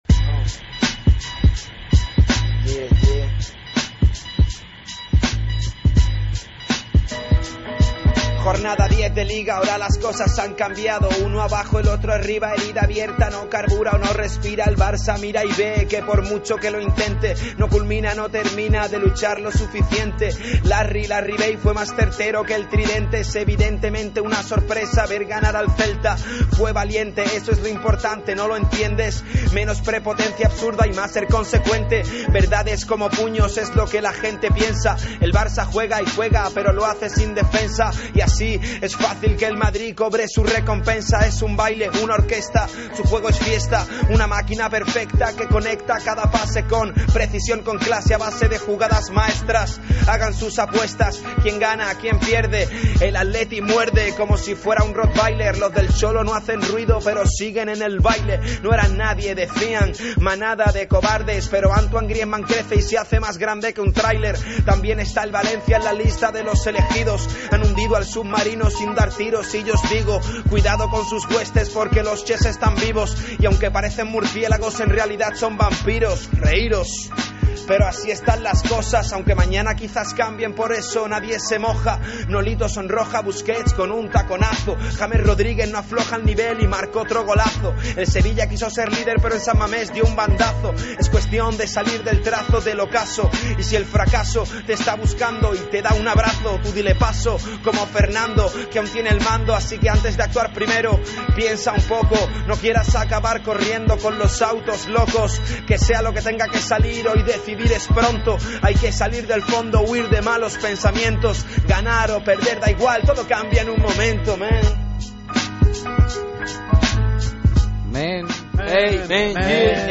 Cerramos Tiempo de Juego a ritmo de rap